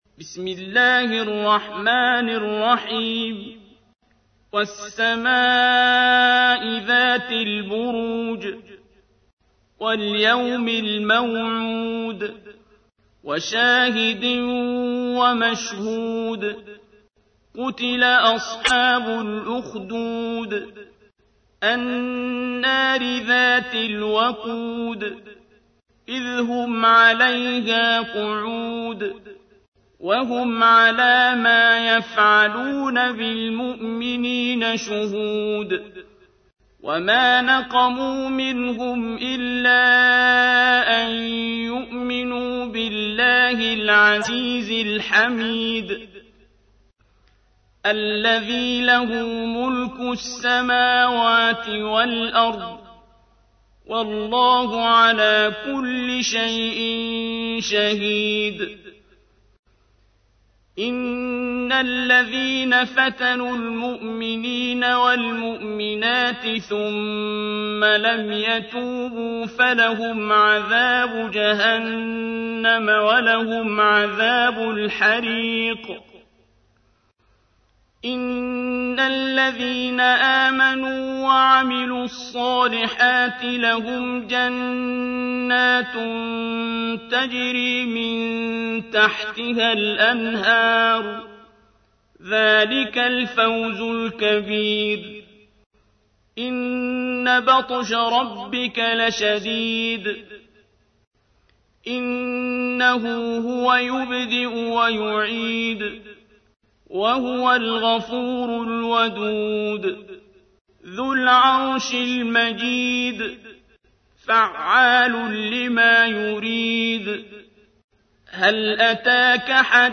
تحميل : 85. سورة البروج / القارئ عبد الباسط عبد الصمد / القرآن الكريم / موقع يا حسين